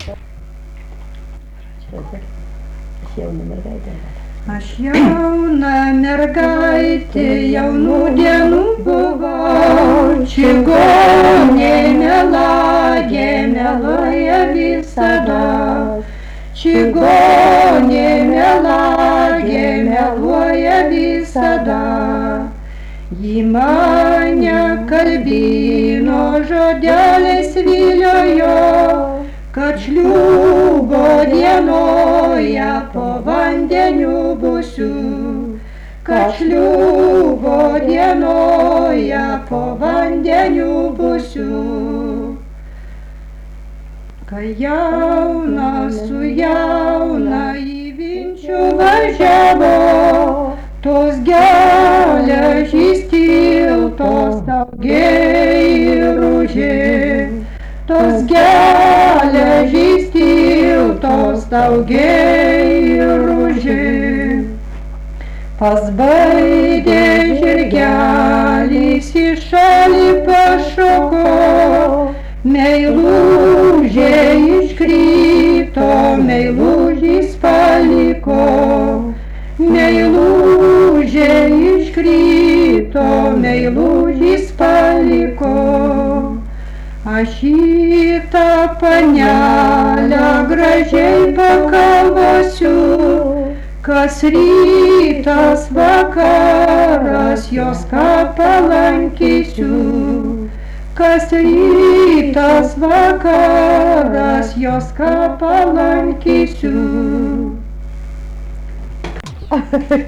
daina
Aleksandravėlė
vokalinis